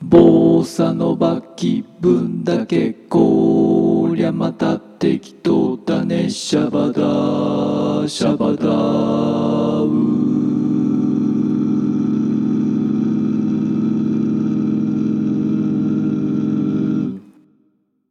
こういうのがあると一人多重コーラスをやってみたいよな。よし、ボサノバごっこだ。
それにしてもひどい音痴だなあ。
bosa_kibun_without_autotune.mp3